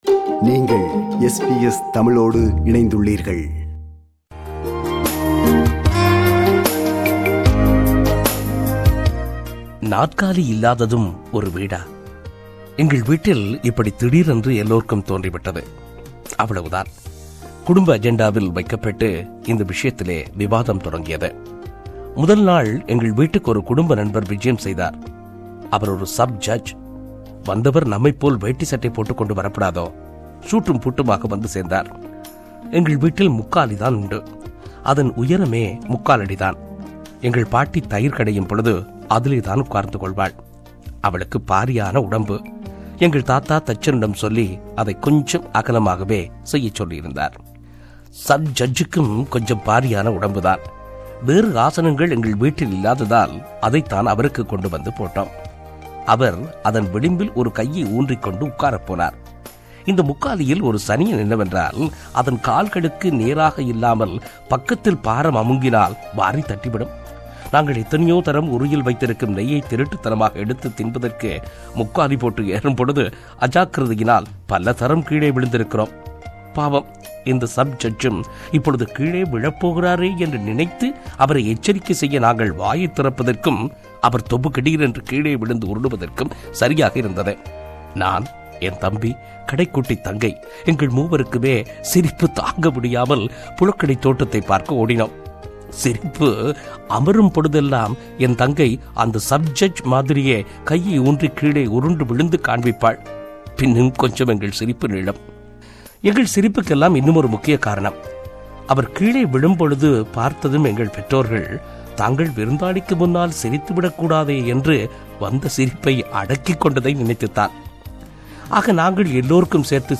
தமிழ் இலக்கிய உலகு மறக்க இயலாத மாபெரும் ஆளுமைகளில் ஒருவர் கடந்த வாரம் மறைந்த ‘கரிசல் இலக்கியத்தின் பிதாமகர்’ என்று அழைக்கப்படும் கி.ரா. அவர்கள். அவரின் "நாற்காலி" (வெளியான ஆண்டு 1969) என்ற சிறுகதையை ஒலிவடிவில் பதிவிடுகிறோம்.